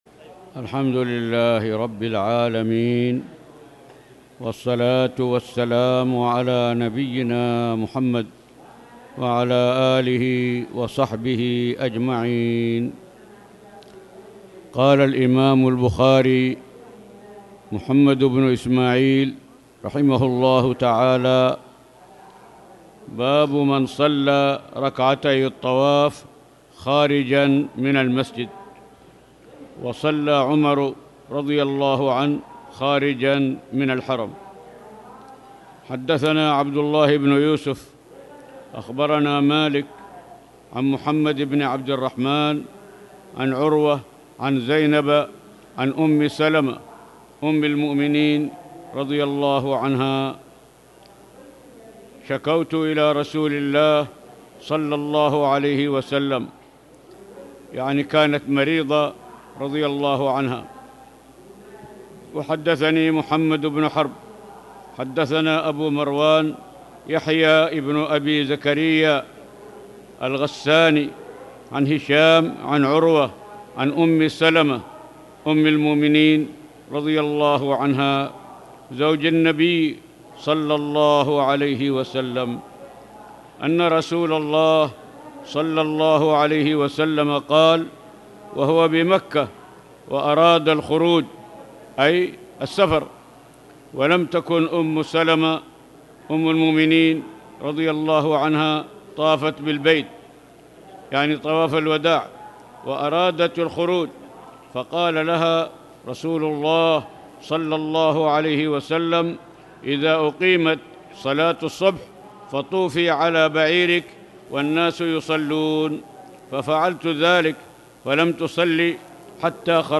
تاريخ النشر ٢ صفر ١٤٣٨ هـ المكان: المسجد الحرام الشيخ